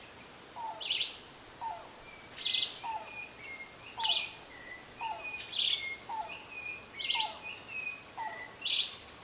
PAPA-LAGARTA-DE-EULER
Nome em Inglês: Pearly-breasted Cuckoo
Canto gravado por
Local:RPPN Taipa Rio do Couro II - Itaiópolis, SC